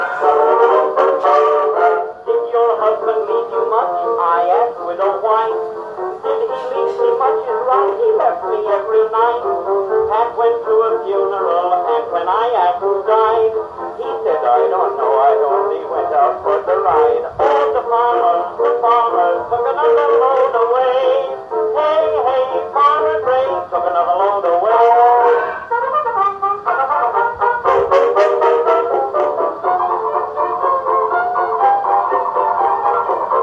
But more than looking nice, this machine has been mechanically restored to play as nice as it looks.
The reproducer was also rebuilt.